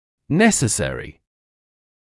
[‘nesəsərɪ][‘нэсэсэри]необходимый